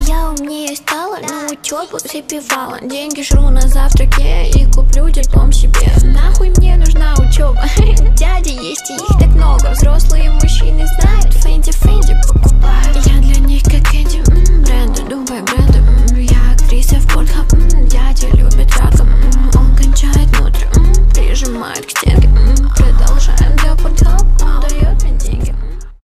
бесплатный рингтон в виде самого яркого фрагмента из песни
Рэп и Хип Хоп